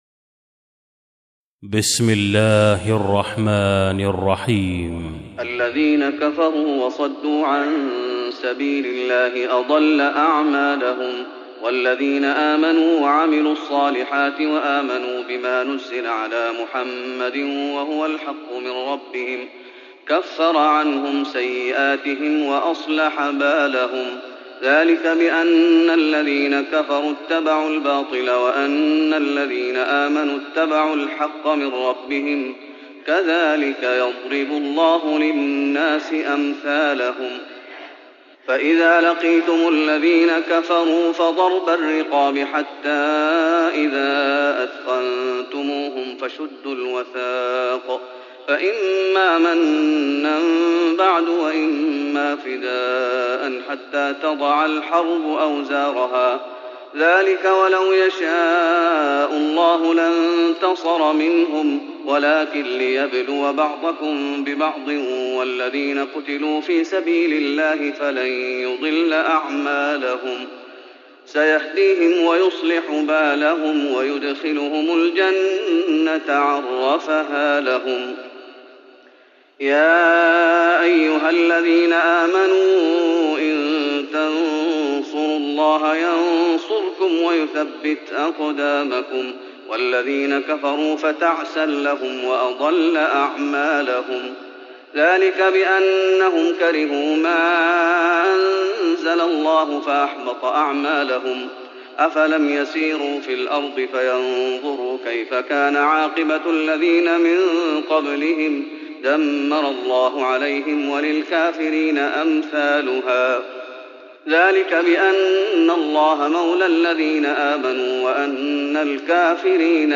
تراويح رمضان 1415هـ من سورة محمد Taraweeh Ramadan 1415H from Surah Muhammad > تراويح الشيخ محمد أيوب بالنبوي 1415 🕌 > التراويح - تلاوات الحرمين